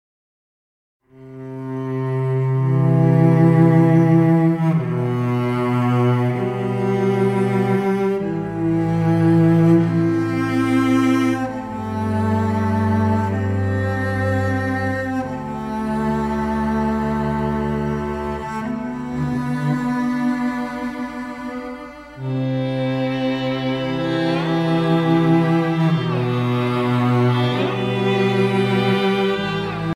Relaxing Violin and Heavenly Cello Music